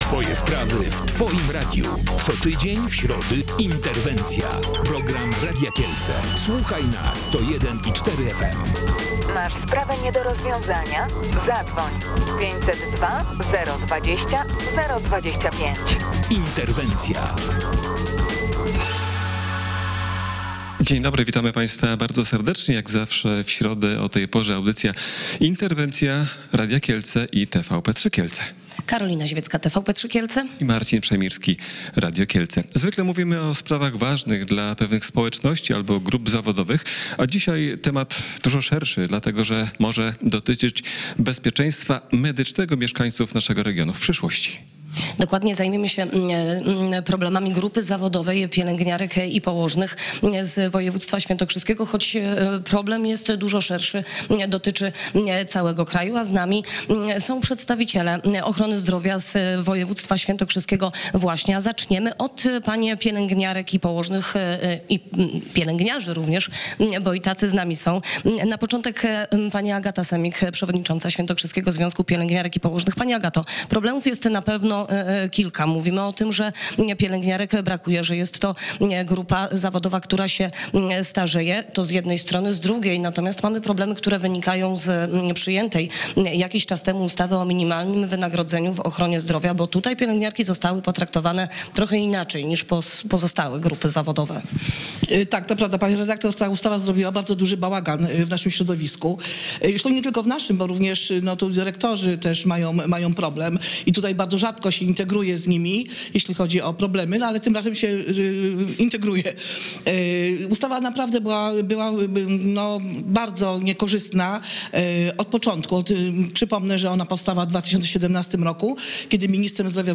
O tym rozmawialiśmy w programie Interwencja.